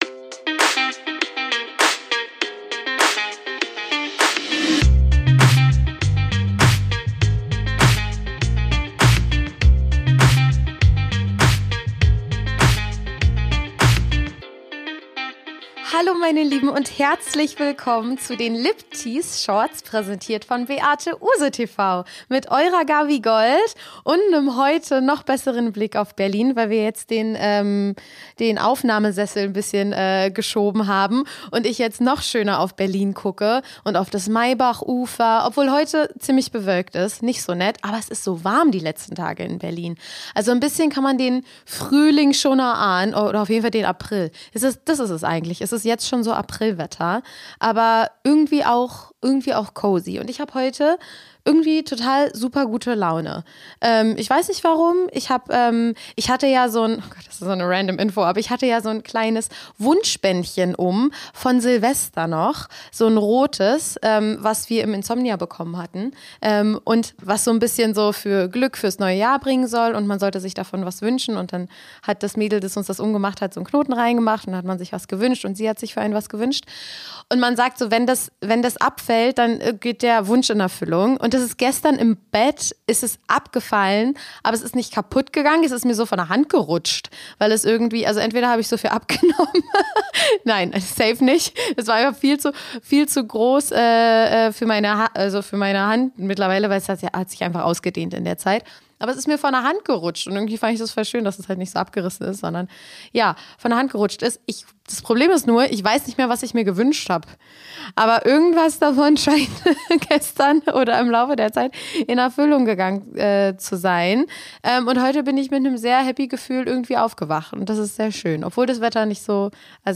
Wir brainstormen vor dem Mikro über schlimme Dinge, die die Spielenden beim Pen and Paper-Rollenspiel so machen.